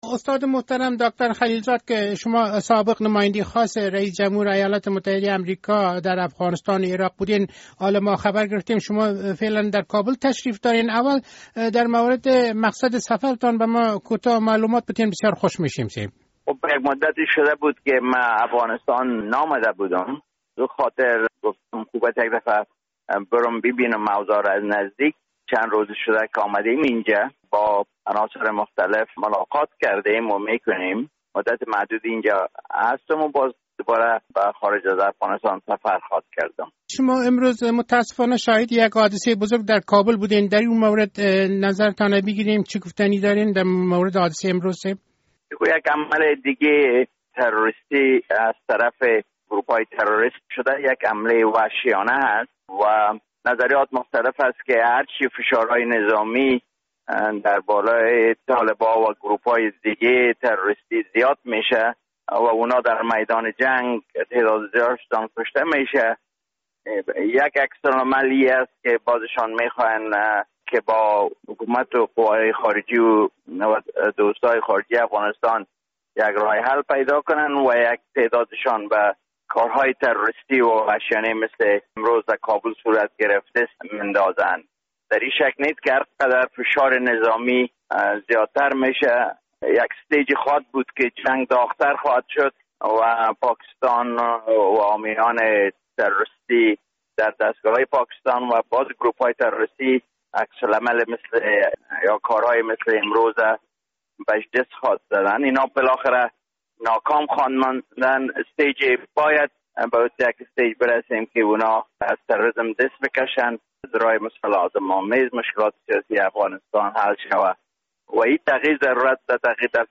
مصاحبه با داکتر زلمی خلیل‌زاد: